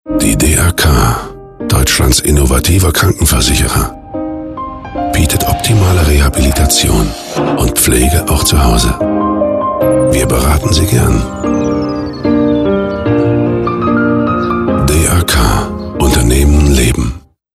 1. Vertrauensvolle Stimme
Ruhig, seriös, glaubwürdig.